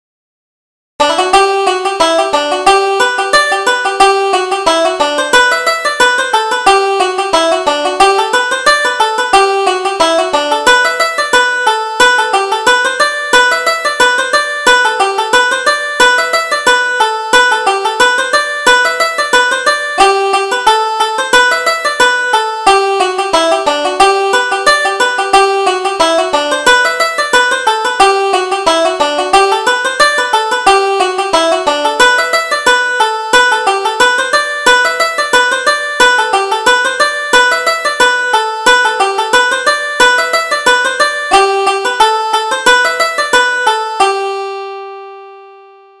Reel: Downing's Reel